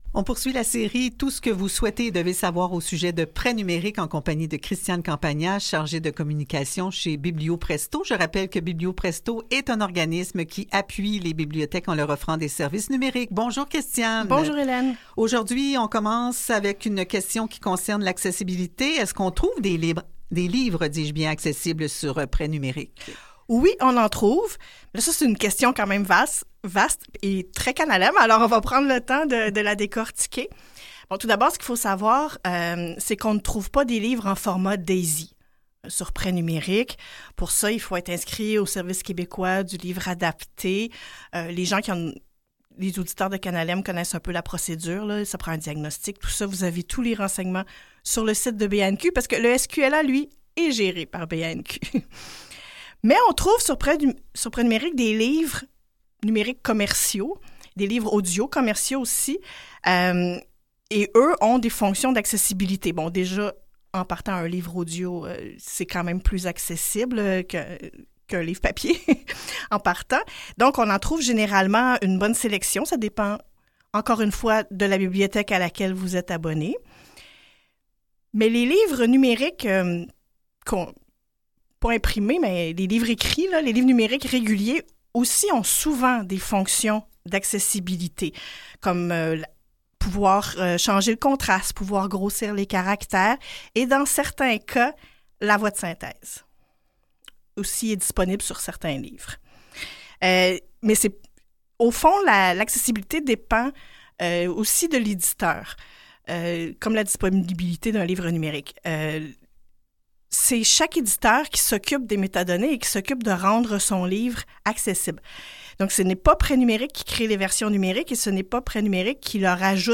Entrevue sur Pretnumerique, 2e partie